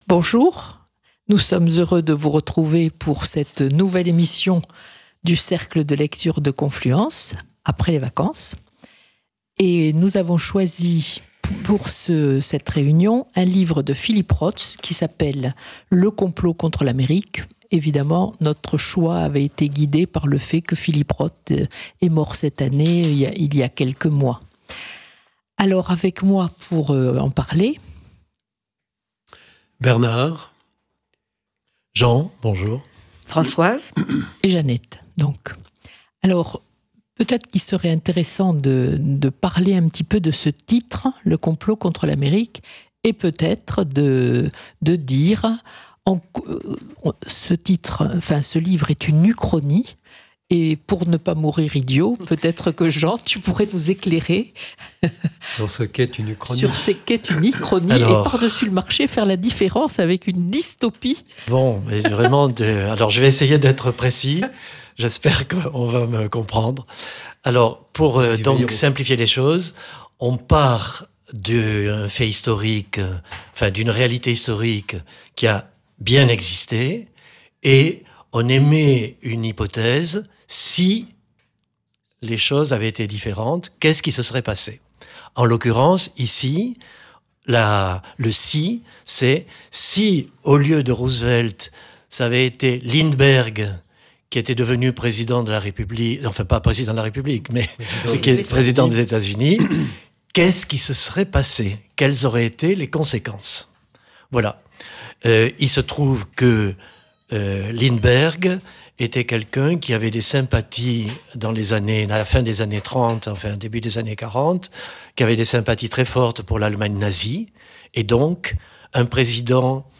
Il y a aussi des participants occasionnels..., CFM montauban